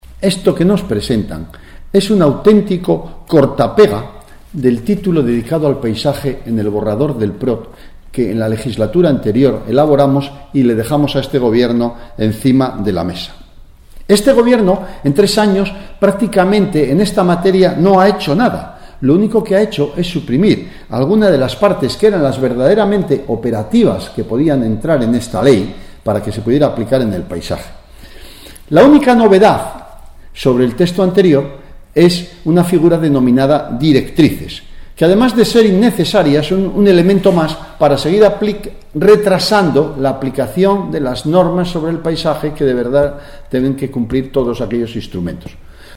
El diputado regionalista, José Mª Mazón, ha anunciado, esta mañana, la enmienda a la totalidad al proyecto de Ley de Paisaje que el grupo regionalista defenderá próximamente en sesión parlamentaria.
El regionalista ha explicado, en rueda de prensa, que esta enmienda a la totalidad responde a la necesidad de que el gobierno “reelabore” el proyecto presentado con verdaderas medidas de aplicación, puesto que el actual documento es sólo un “simple corta y pega” del borrador que el anterior ejecutivo dejó incluido en el Plan Regional de Ordenación Territorial de Cantabria (PROT) y del que se han suprimido las partes verdaderamente operativas.